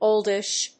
音節óld・ish 発音記号・読み方
/‐dɪʃ(米国英語)/